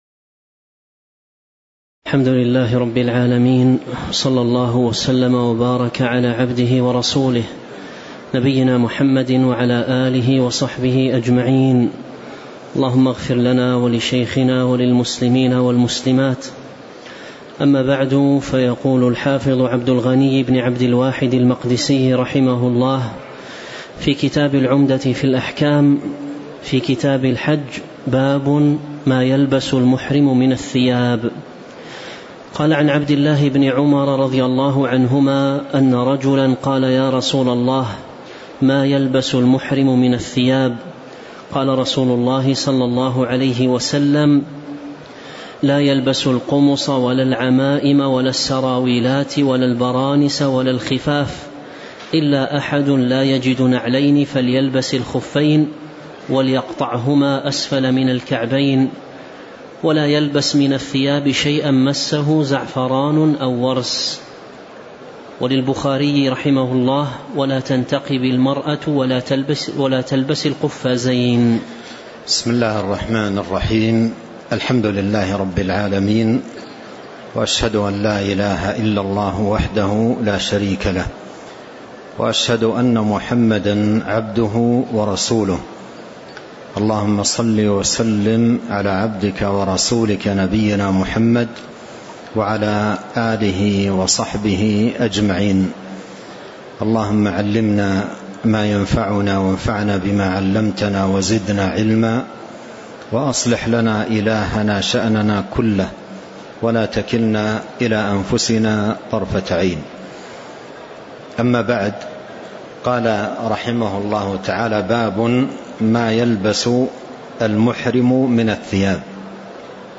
تاريخ النشر ١ محرم ١٤٤٣ هـ المكان: المسجد النبوي الشيخ